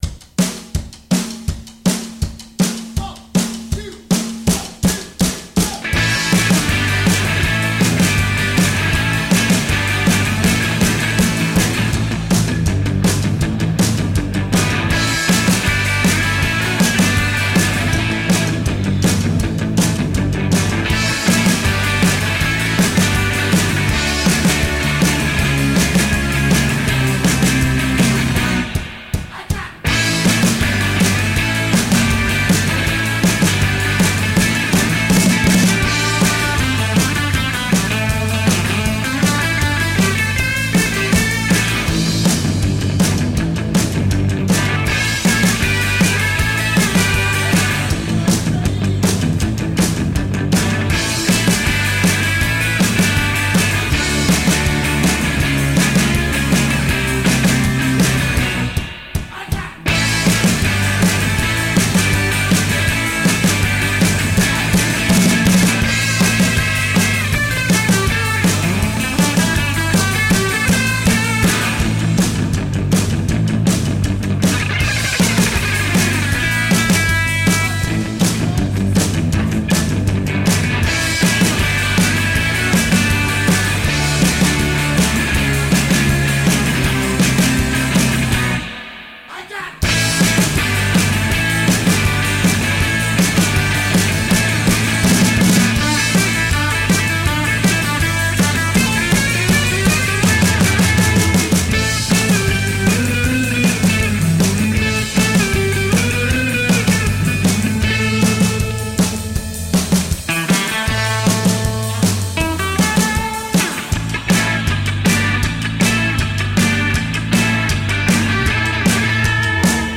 Nippy post-punk retro rock with a bite.
bass
drums
guitar
Tagged as: Alt Rock, Hard Rock, Instrumental, Ironic Rock